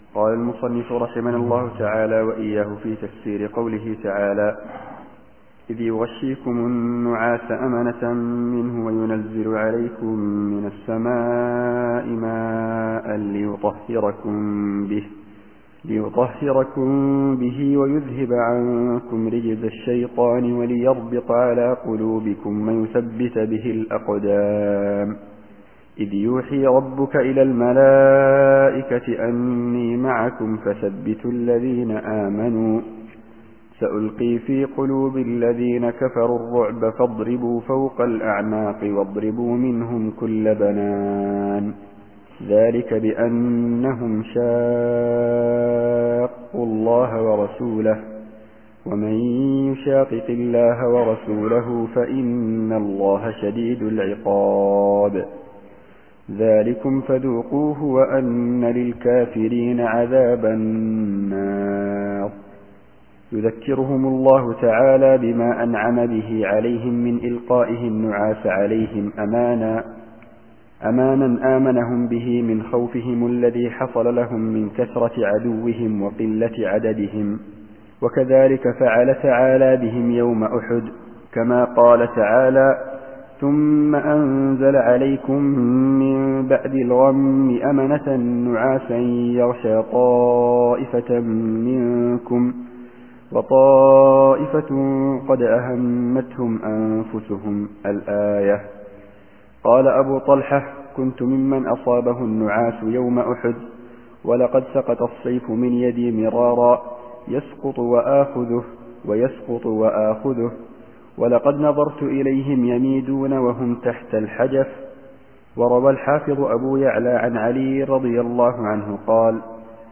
التفسير الصوتي [الأنفال / 11]